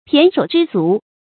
胼手胝足 pián shǒu zhī zú
胼手胝足发音